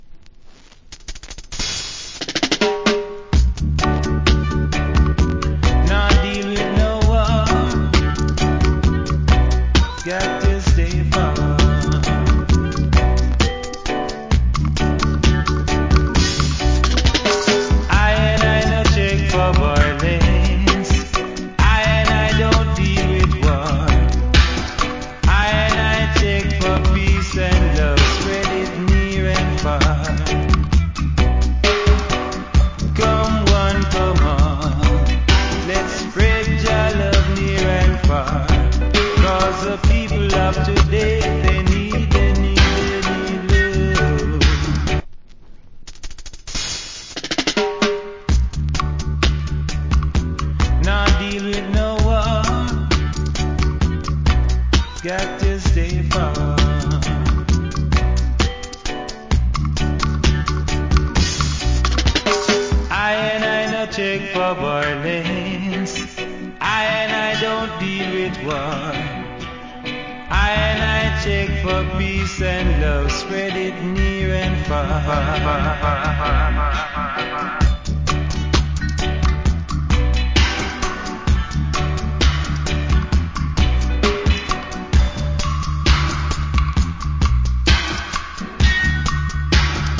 Cool Roots.